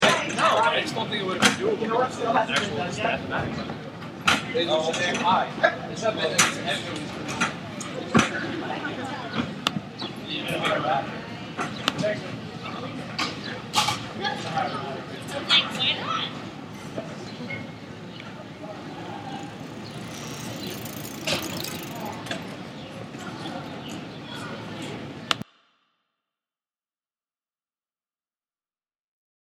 Location: Outside Lowe early in the morning
Sounds Heard: Birds chirping, The trees doing that thing that they do with the breezes.
MId-day-outside.mp3